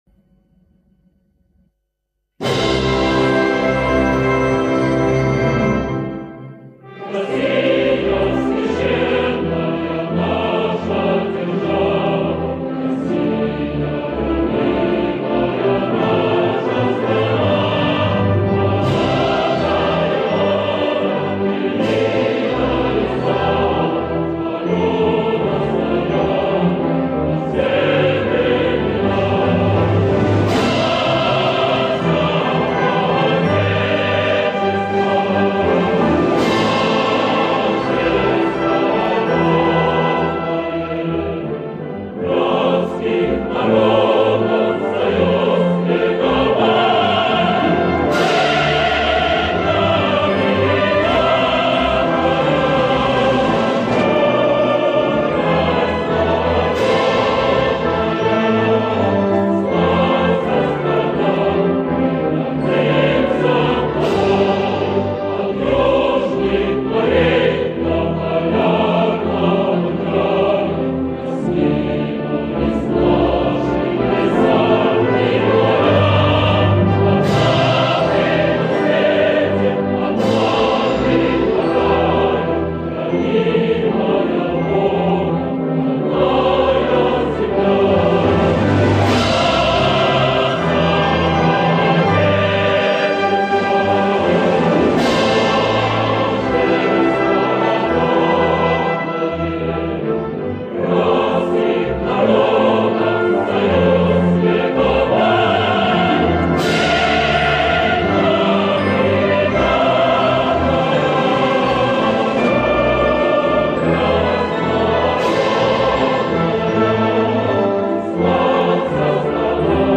National anthem of Russia | Rusya’nın millî marşı : Gosudarstvenny gimn Rossiyskoy Federatsii”